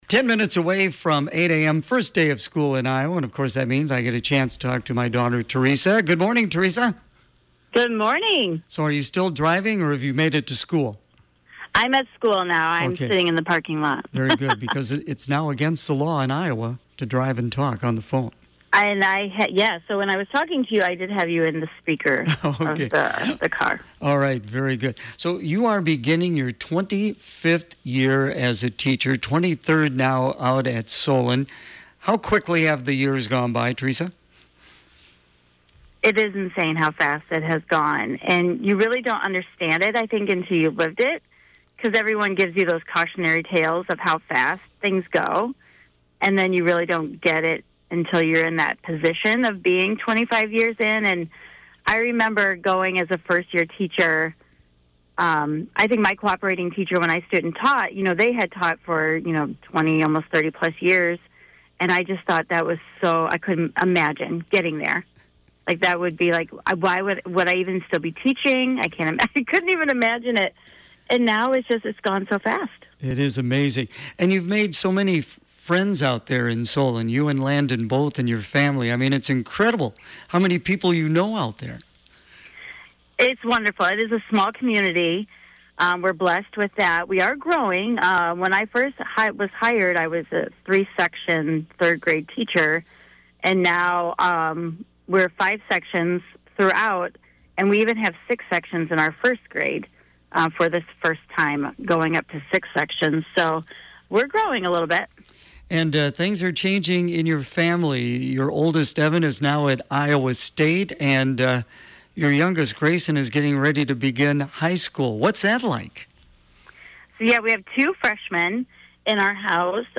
First Day of School Interview